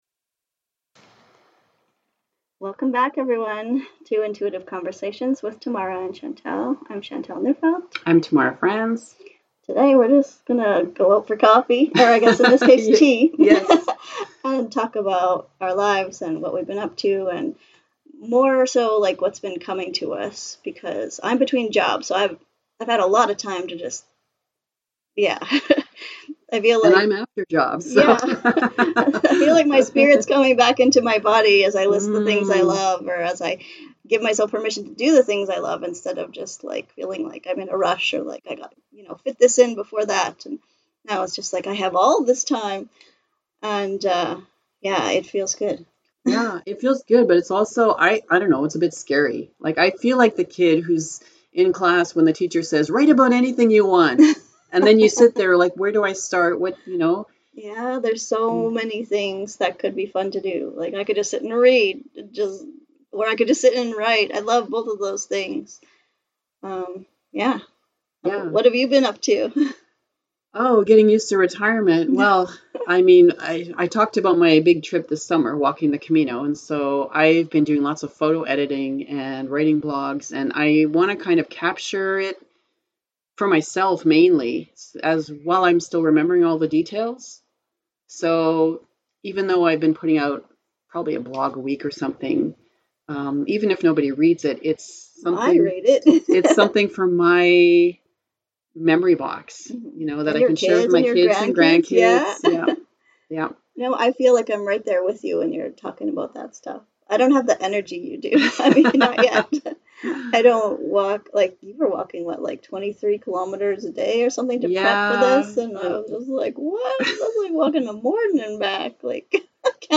A spontaneous chat about what's going on in our lives and what's on our minds...